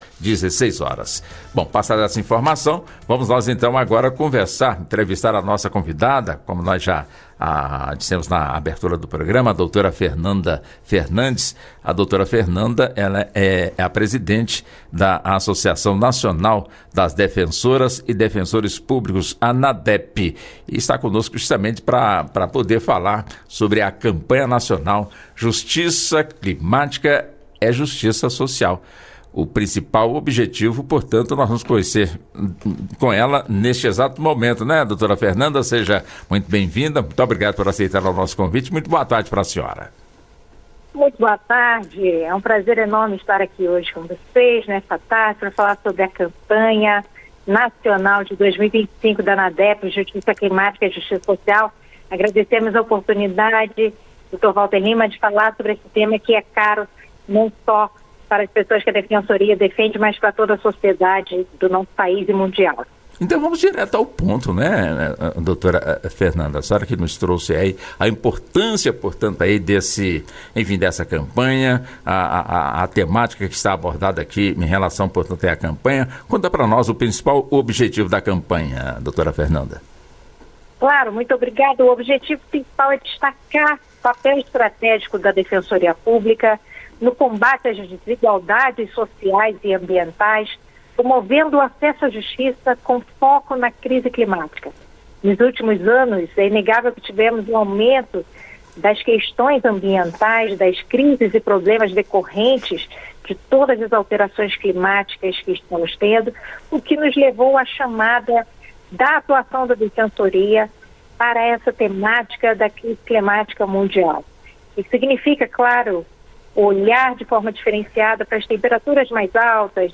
concedeu entrevista ao programa "Justiça na Tarde"